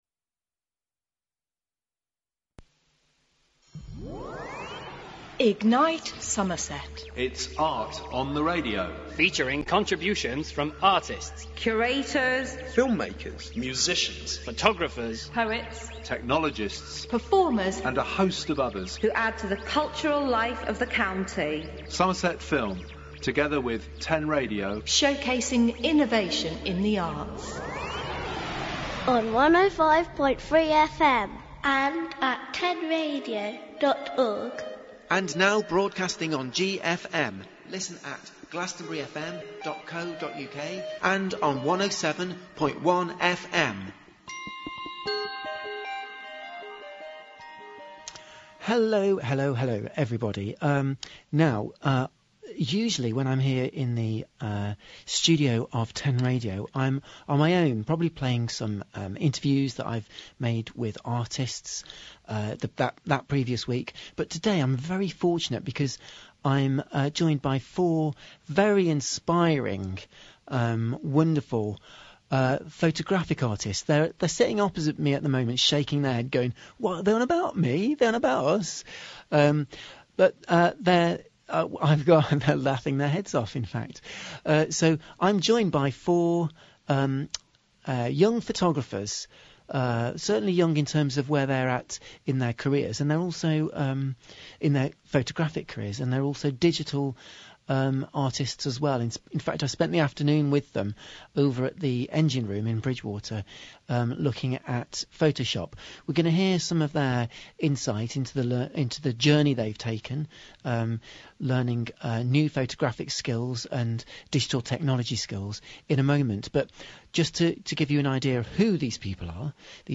Photography Group Interviewed on 10Radio
10Radio-Show-U3A-Photography-Group.mp3